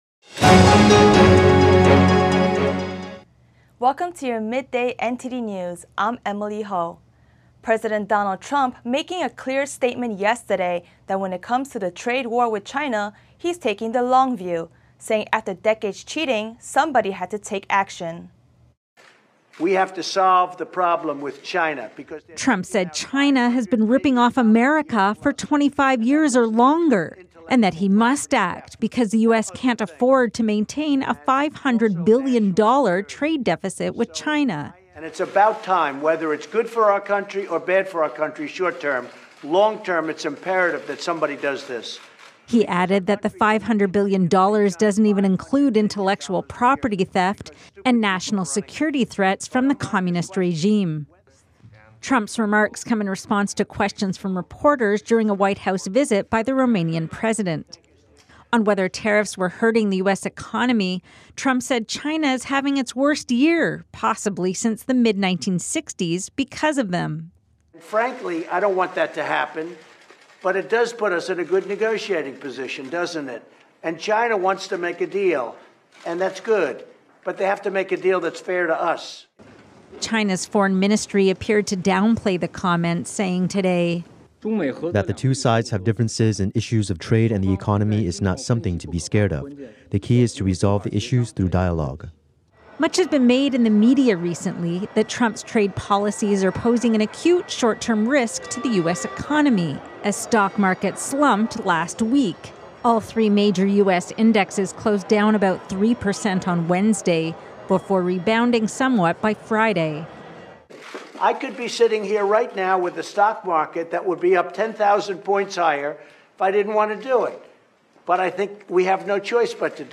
NTD News Today Full Broadcast (Aug. 21)